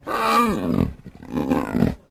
sounds_leopard_growl_03.ogg